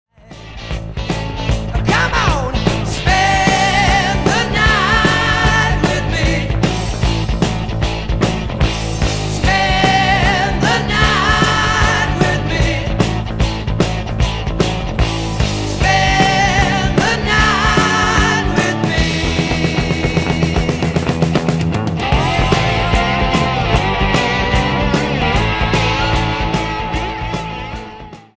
Recorded at Soundstage, Toronto.